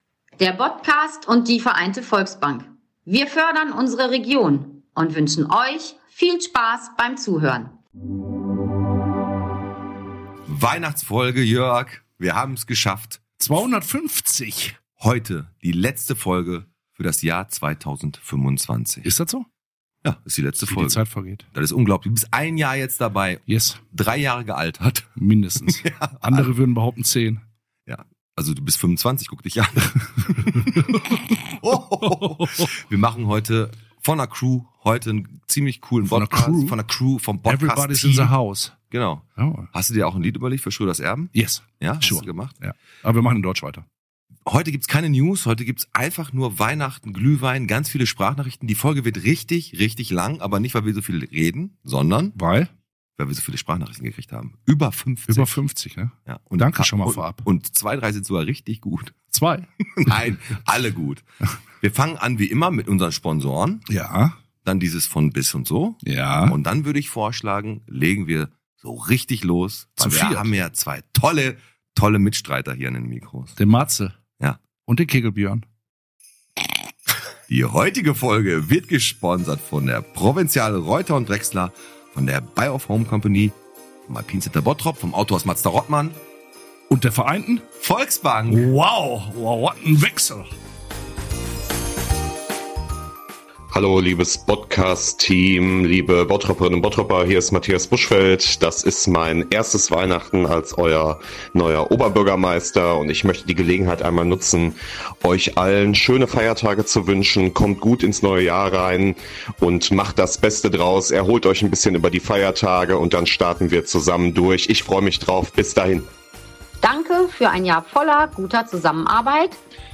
Gemeinsam mit Euch und Euren vielen, vielen Sprachnachrichten.